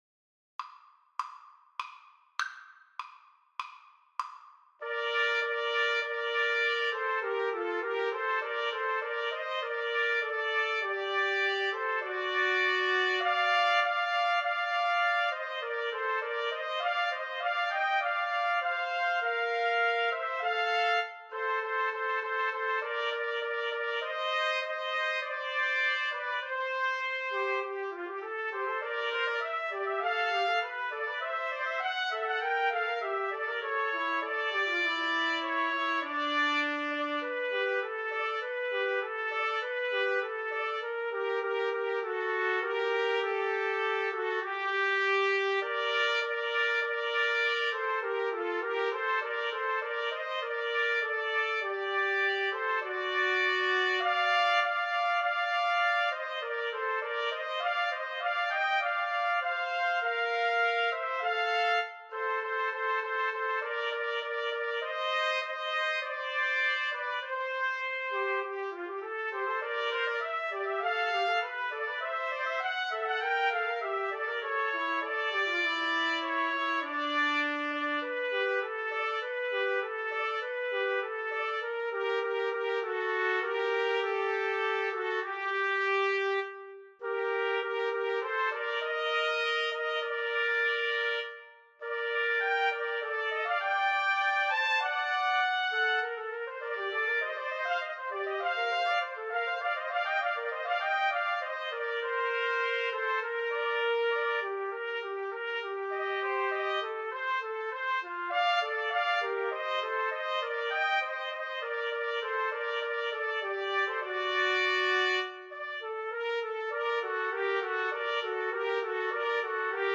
Play (or use space bar on your keyboard) Pause Music Playalong - Player 1 Accompaniment Playalong - Player 3 Accompaniment reset tempo print settings full screen
Trumpet 1Trumpet 2Trumpet 3
Bb major (Sounding Pitch) C major (Trumpet in Bb) (View more Bb major Music for Trumpet Trio )
[Moderato]
4/4 (View more 4/4 Music)
Classical (View more Classical Trumpet Trio Music)